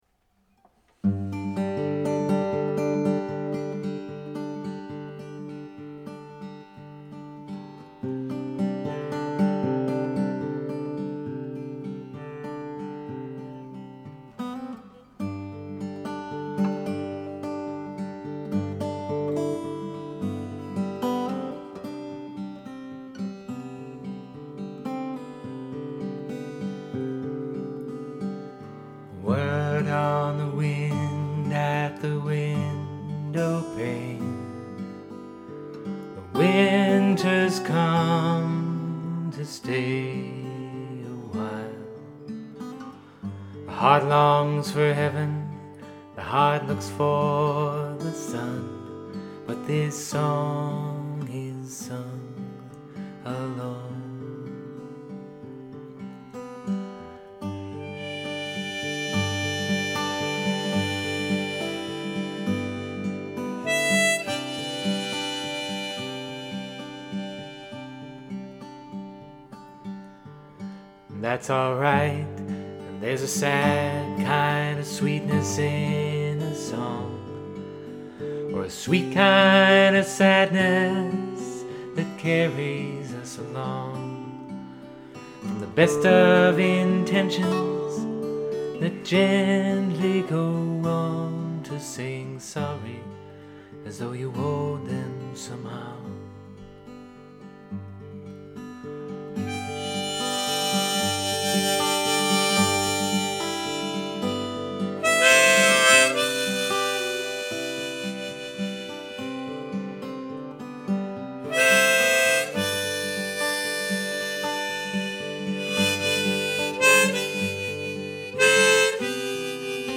The original version of this song (the one with the nose still on it) is somewhere on this blog, written not long ago; a couple of takes made this version clean enough to offer, my excuse being that imperfection makes it human.